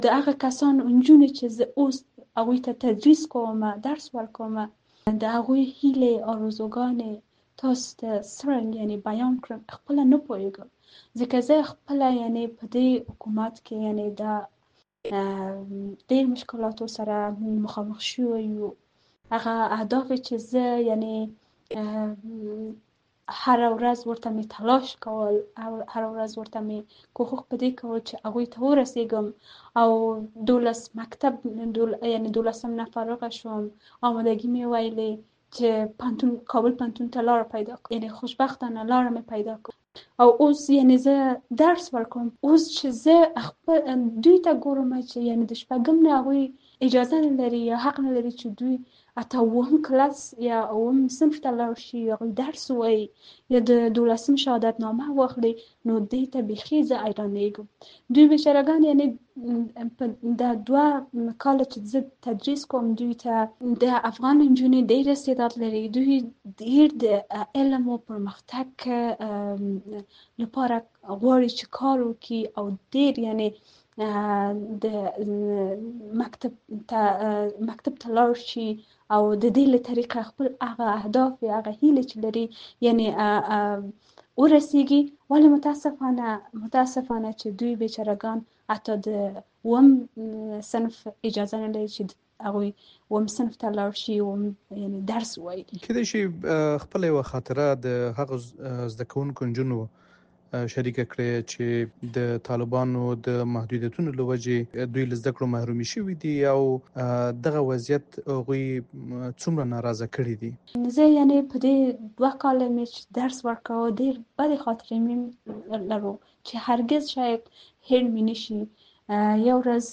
راډیويي مرکې